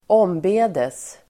Ladda ner uttalet
Uttal: [²'åm:be:des]